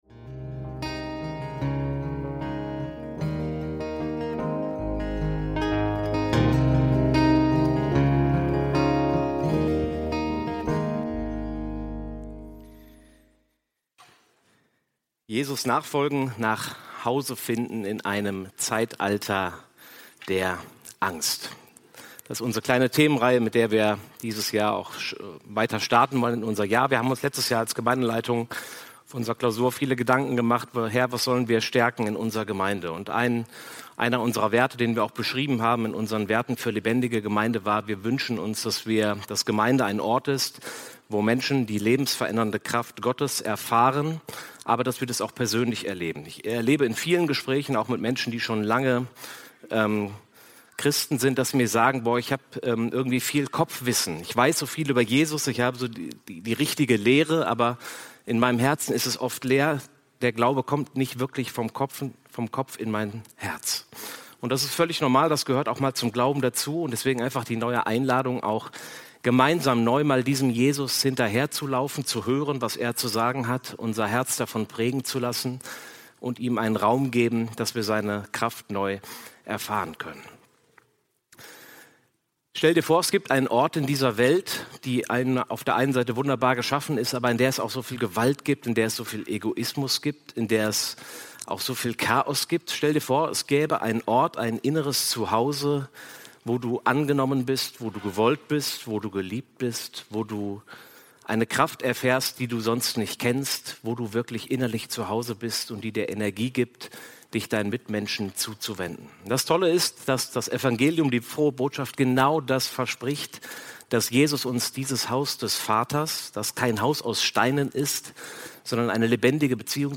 Die Einladung "Komm und sieh" – Jesus nachfolgen: Nach Hause finden in einem Zeitalter der Angst – Predigt vom 02.02.2025 ~ FeG Bochum Predigt Podcast